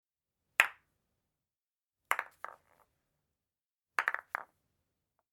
Крокет, удары по мячу
Тут вы можете прослушать онлайн и скачать бесплатно аудио запись из категории «Спорт, игры, отдых».